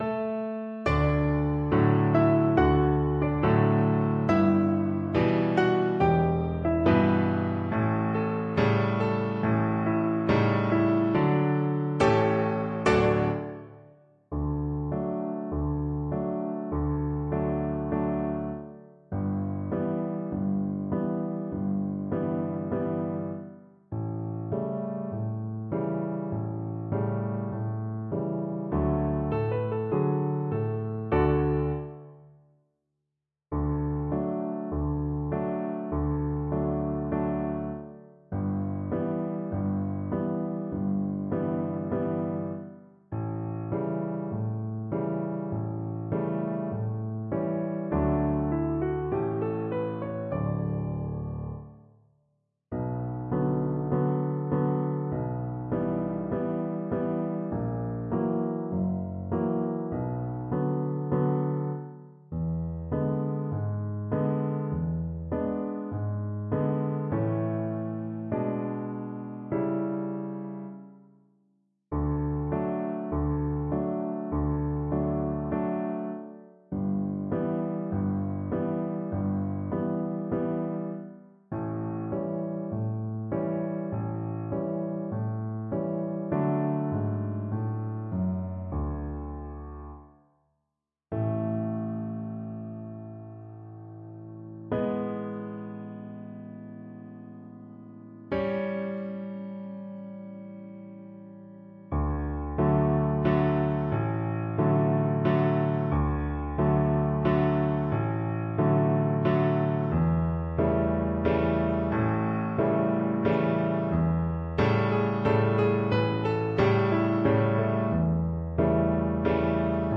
Cello
4/4 (View more 4/4 Music)
Marcia
Arrangement for Cello and Piano
D major (Sounding Pitch) (View more D major Music for Cello )